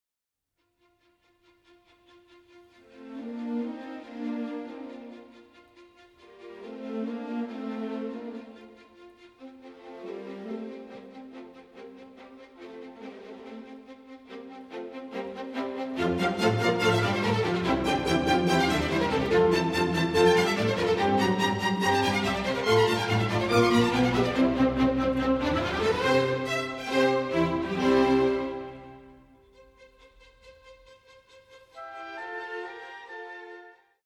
Allegro moderato 12:08